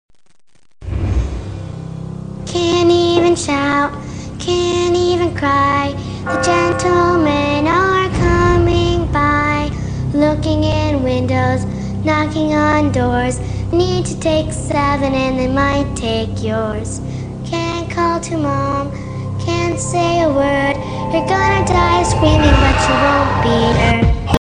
Musique issue de l’album: DVD rip